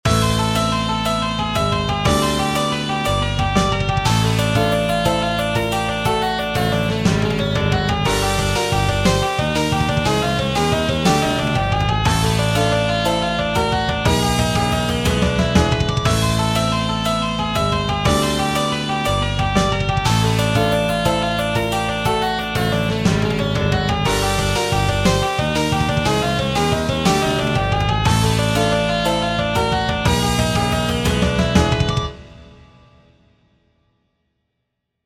Short 120bpm loop in 17edo
17edo_demo_2.mp3